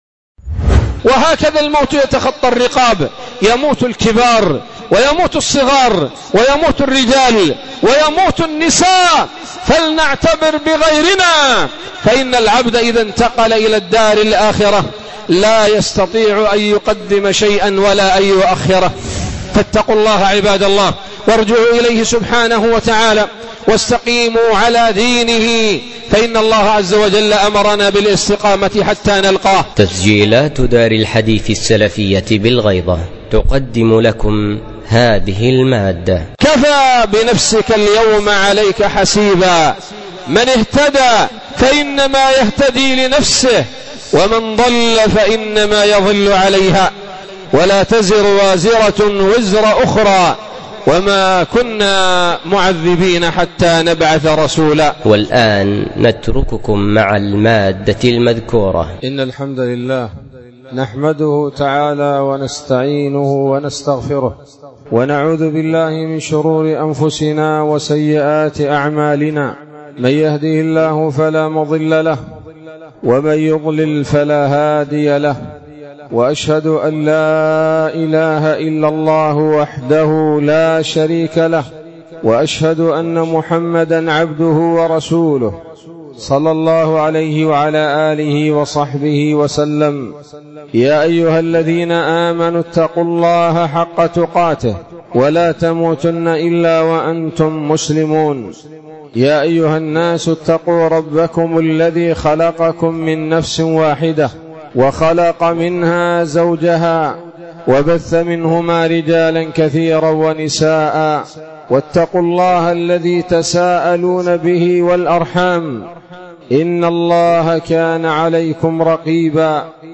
خطبة جمعة بعنوان: (( النصح الجلل بالإقبال والرجوع إلى الله عز وجل )) 15 شوال 1444 هـ، دار الحديث بالغيضة - المهرة